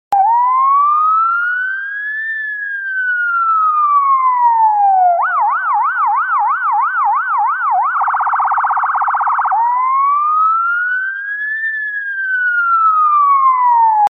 Ambulance 1
ambulance-1.mp3